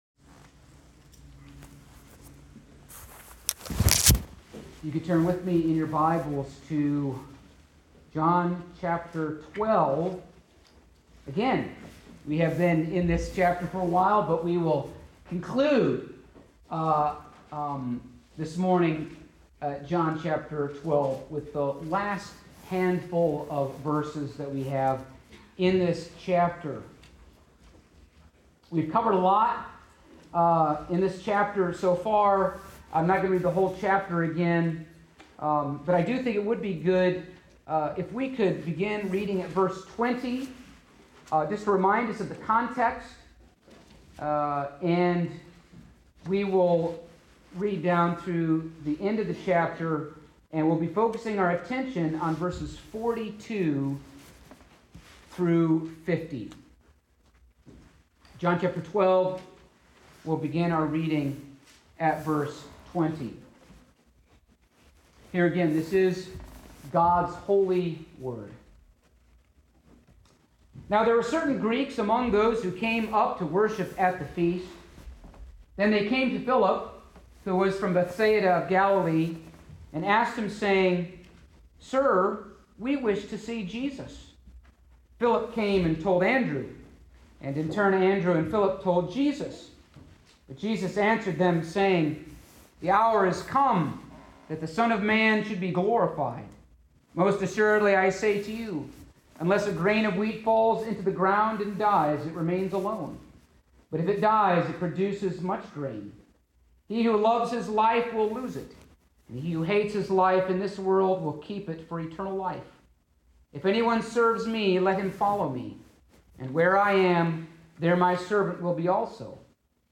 Passage: John 12:42-50 Service Type: Sunday Morning